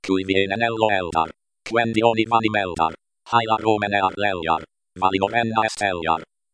By espeak-ng TTS
abc-romen-Espeak.mp3